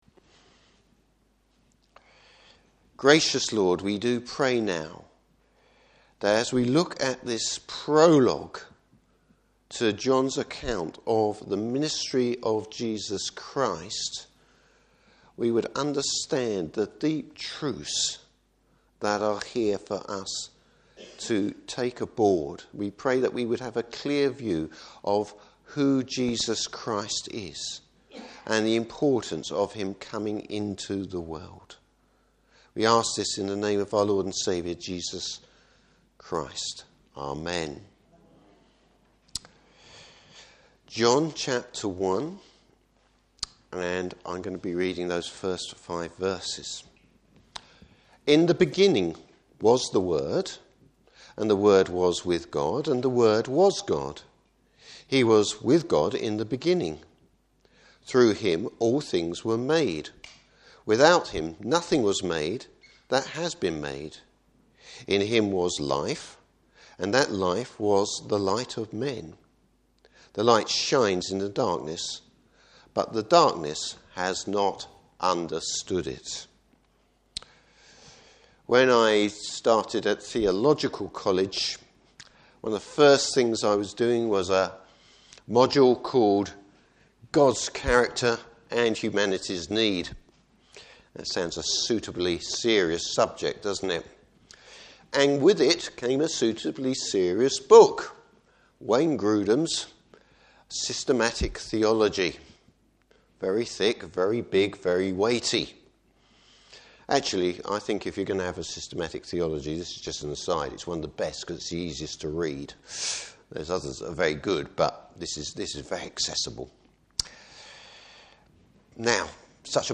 Service Type: Morning Service Jesus, the light and the life.